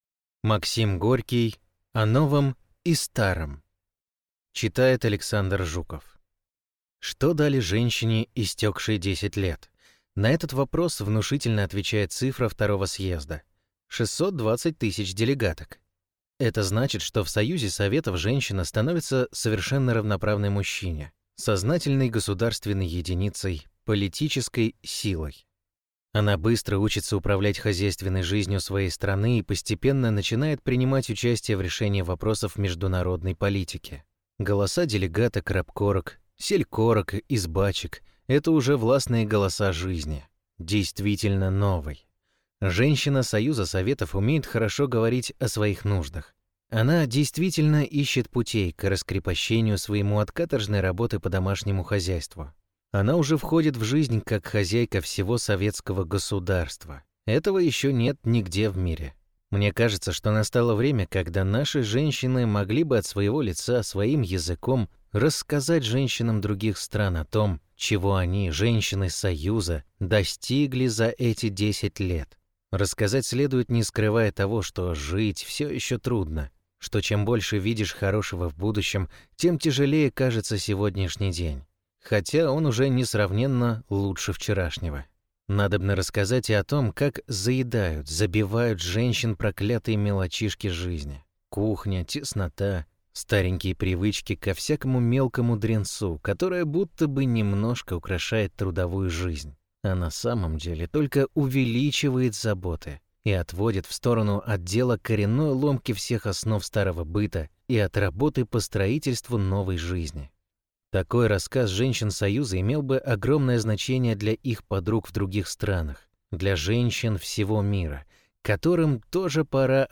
Аудиокнига О новом и старом | Библиотека аудиокниг